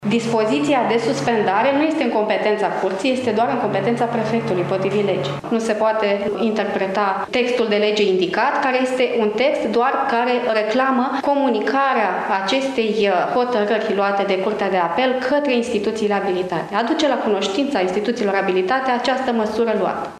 Judecătoarea Cristina Maria Popa de la Curtea de Apel Iaşi, a declarat că dispoziţia de suspendare a lui Cristian Adomniţei din funcţia de preşedinte al Consiliului Judeţean Iaşi, pe durata măsurii preventive a arestului la domiciliu nu intră în competenţa Curţii, ci a prefectului, aşa cum prevede legea.